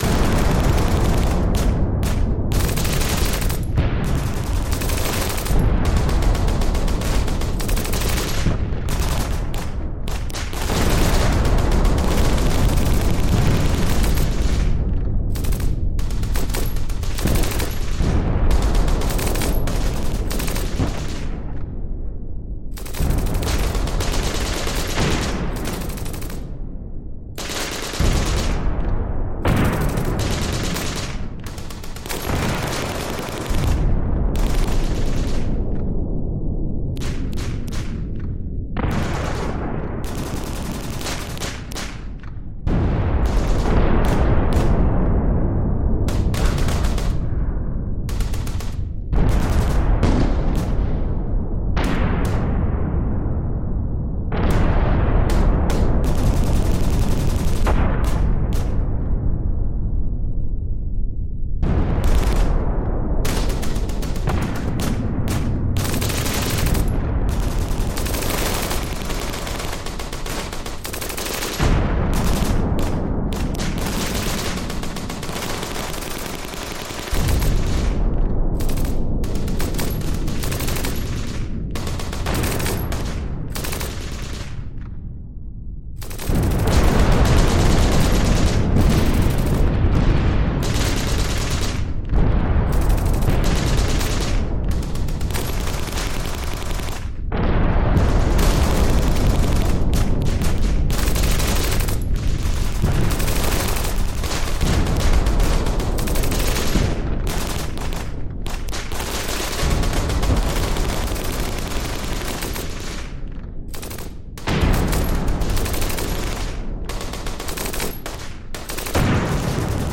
Ожесточенные военные столкновения